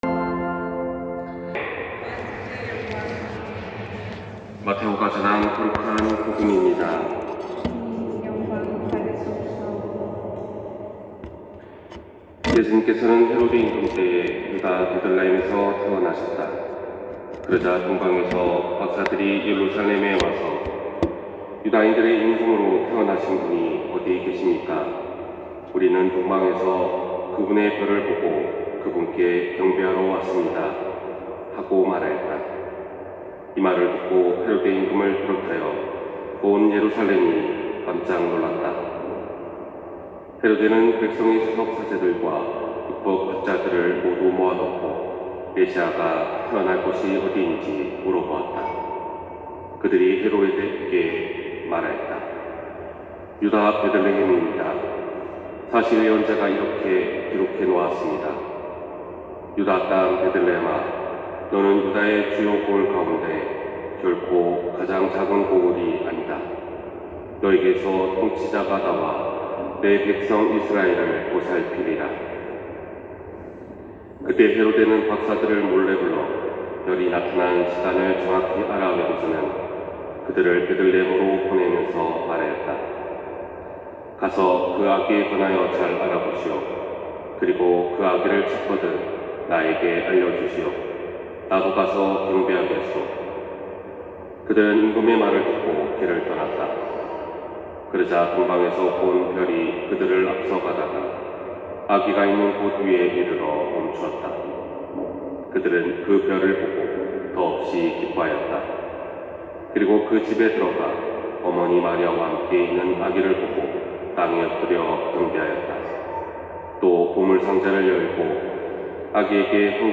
260103 신부님강론맒씀